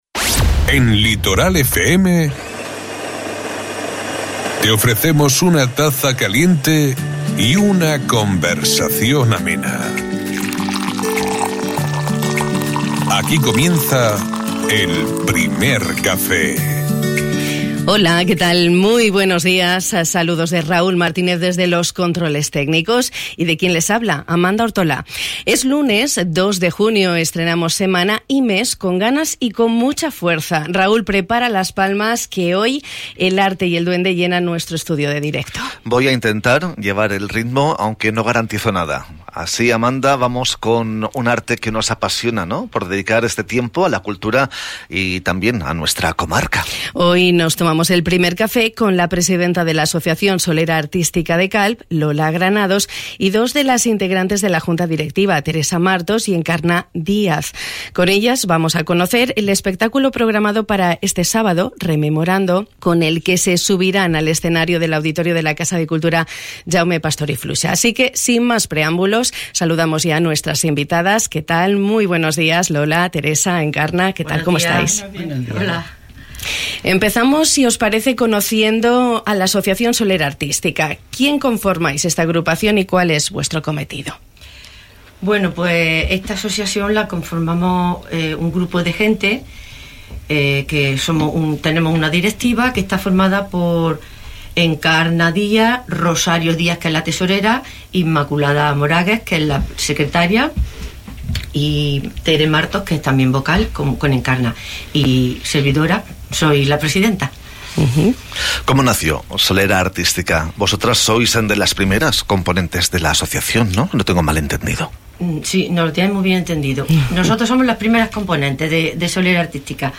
Esta mañana el arte y el duende ha llenado nuestro estudio de directo.